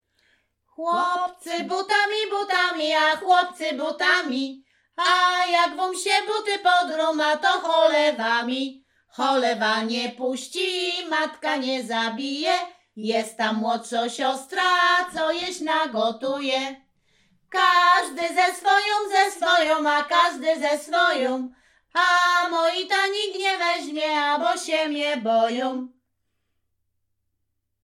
Śpiewaczki z Chojnego
Przyśpiewki
Array przyśpiewki wesele weselne miłosne